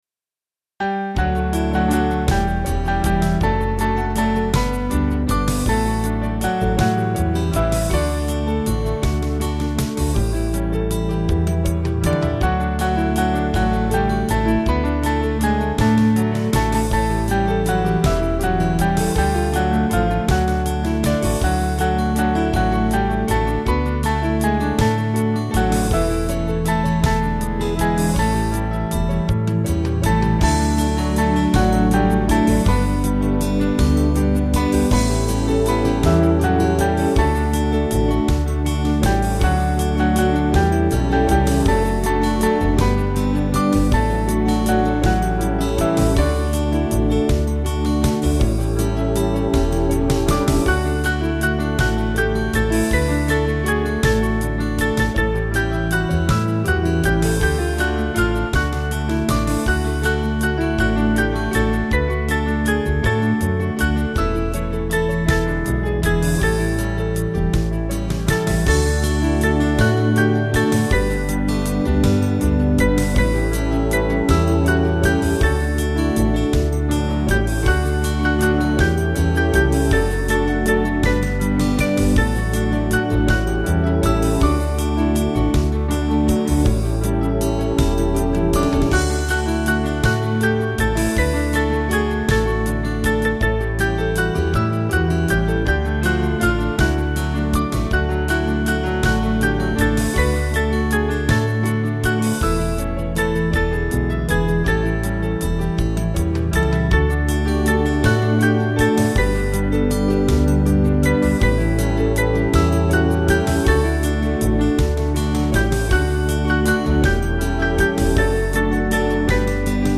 Small Band
4/D-Eb 283kb